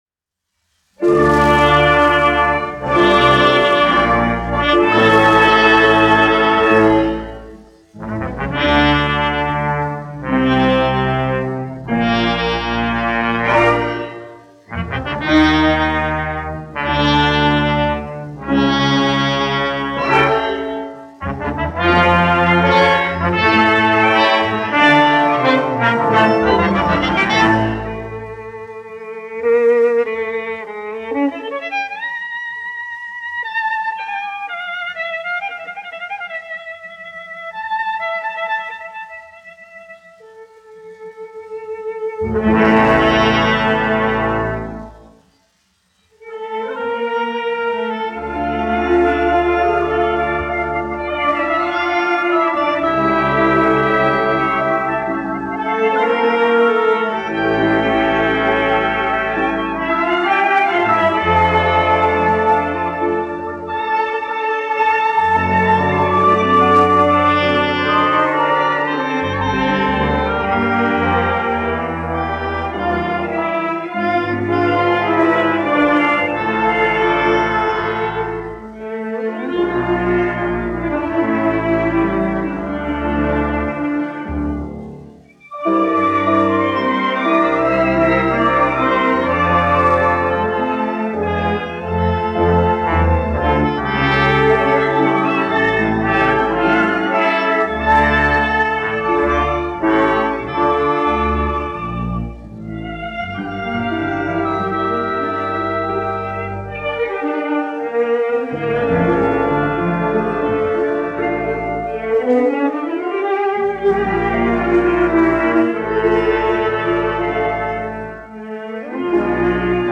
1 skpl. : analogs, 78 apgr/min, mono ; 25 cm
Populārā instrumentālā mūzika
Deju mūzika -- Ungārija
Skaņuplate